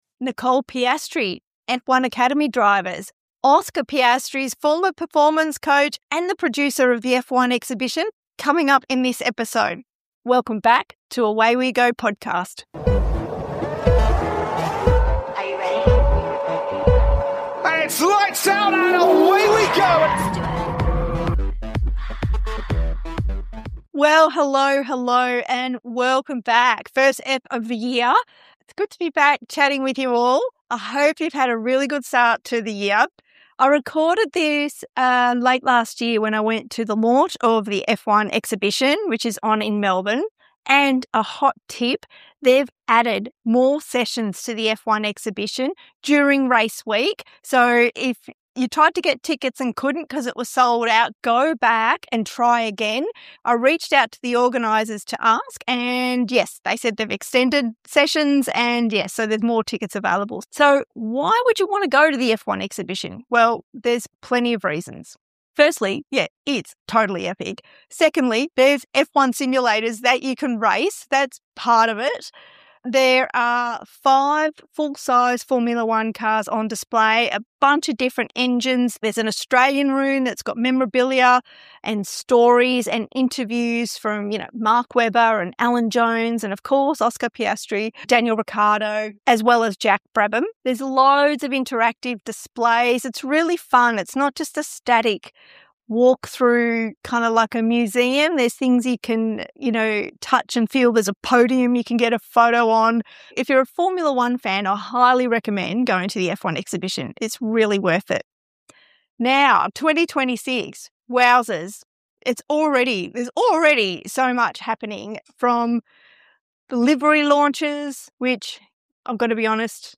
Sky Sports F1 broadcaster and author Rachel Brookes joins Away We Go Podcast for a wide-ranging chat that moves from the chaos (and thrill) of live TV to what it really takes to build a career in elite sports broadcasting.